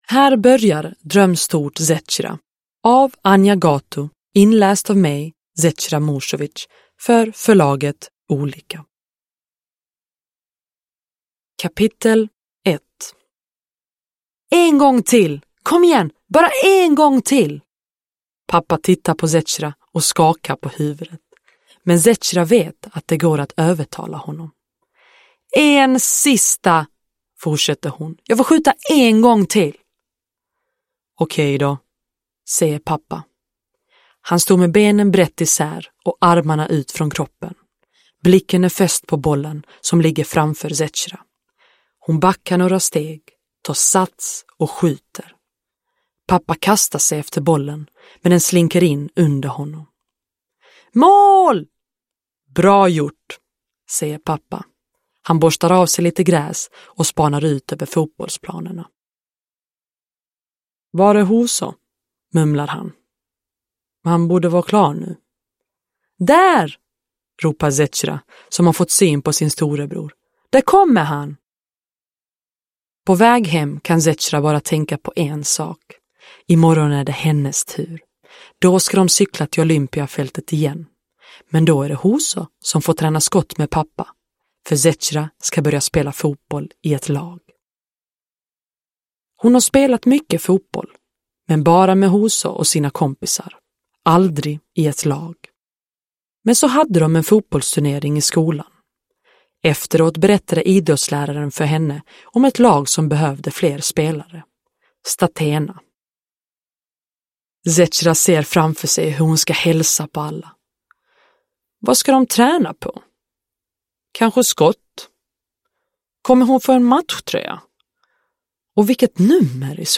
Dröm stort, Zecira! (ljudbok) av Anja Gatu